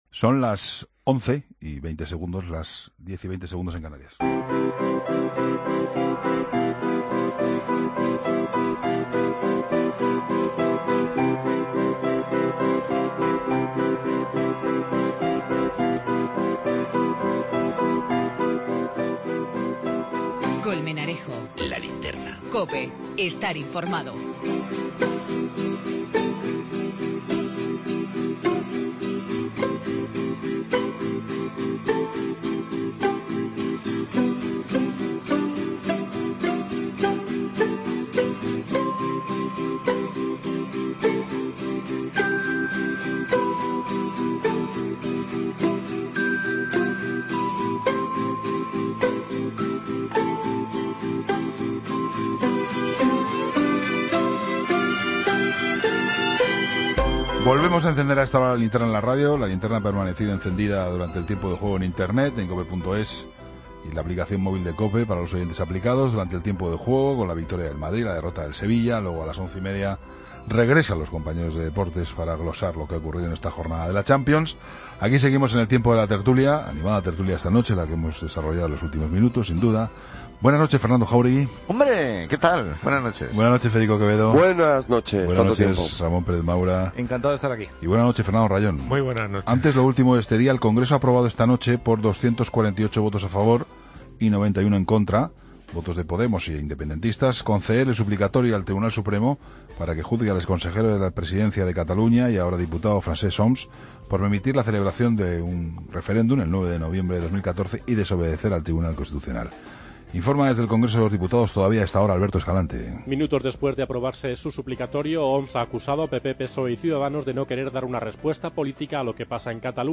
Tertulia II, martes 22 de octubre de 2016